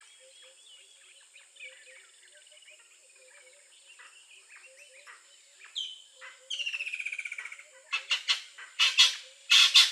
• natal spurfowl african bird.wav
natal_spurfowl_african_bird_LTh.wav